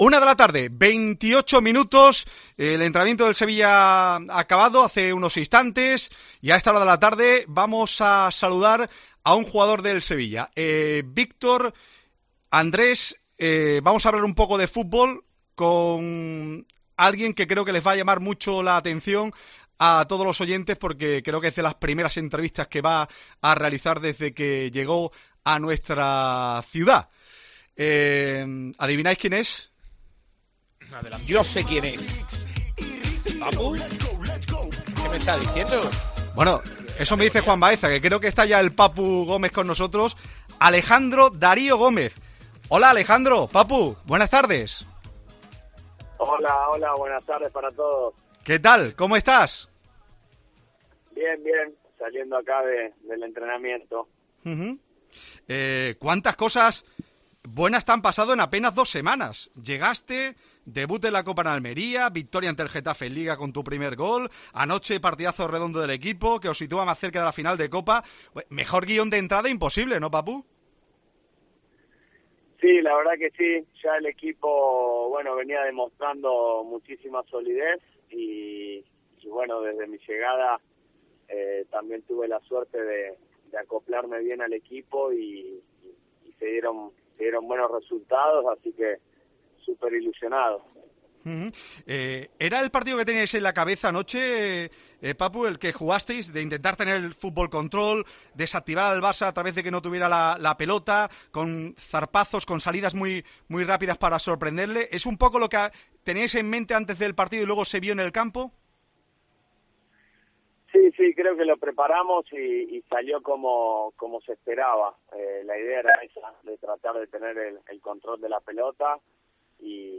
LA ENTREVISTA DEL PAPU GÓMEZ EN COPE MÁS SEVILLA (105.8fm)
El Papu Gómez pasó este jueves por los micrófonos de Cope Más Sevilla para dejar muy claro que no renuncia a nada con su nuevo equipo.